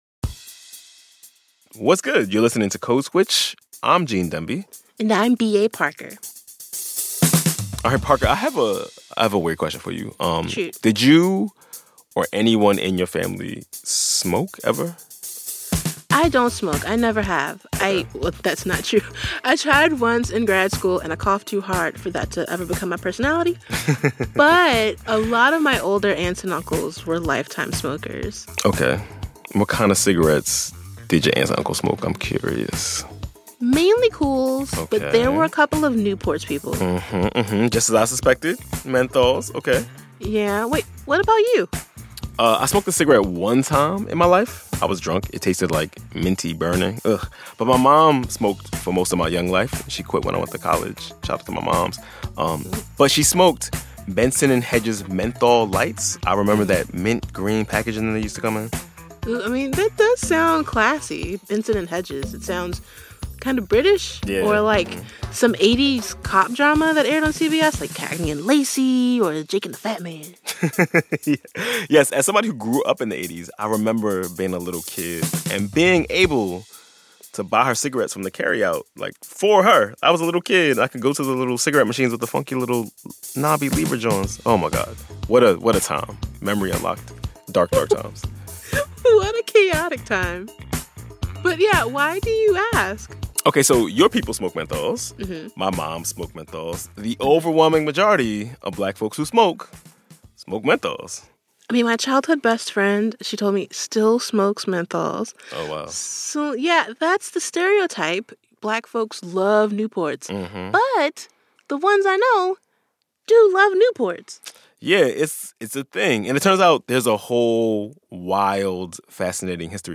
Hosted by journalists of color, our podcast tackles the subject of race with empathy and humor. We explore how race affects every part of society — from politics and pop culture to history, food and everything in between.